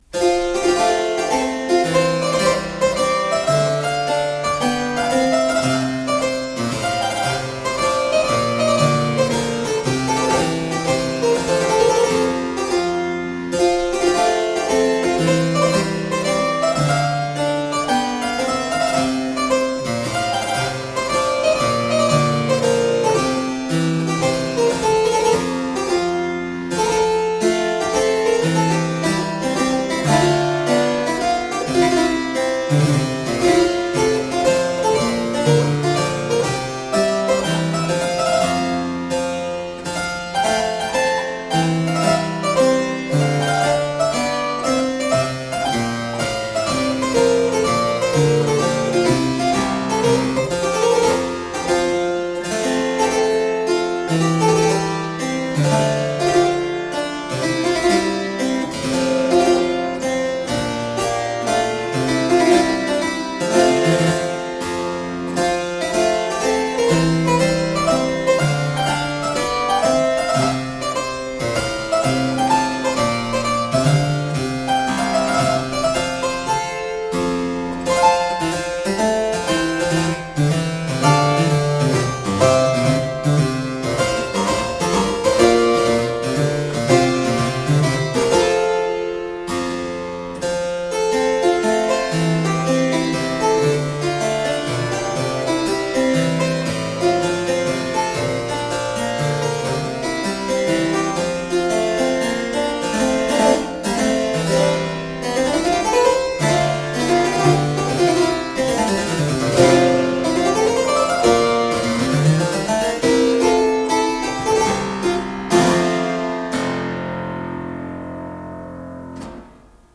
Solo harpsichord music.
Classical, Baroque, Renaissance, Instrumental
Harpsichord